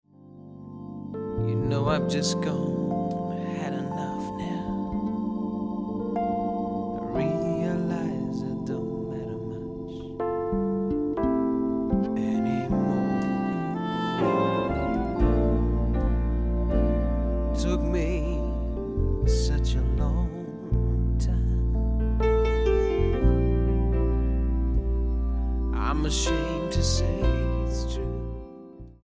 STYLE: Rock
downbeat almost Princelike soul